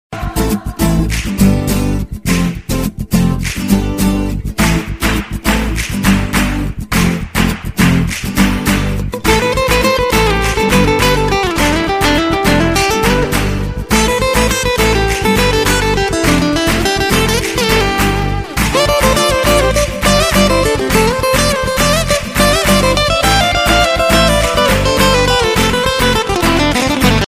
Ringtones